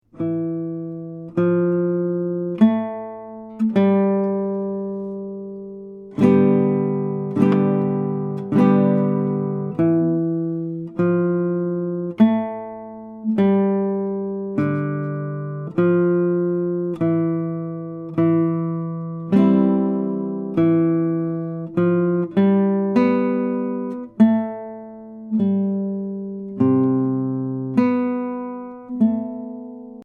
Voicing: Guitar